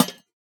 Minecraft Version Minecraft Version snapshot Latest Release | Latest Snapshot snapshot / assets / minecraft / sounds / block / lantern / break6.ogg Compare With Compare With Latest Release | Latest Snapshot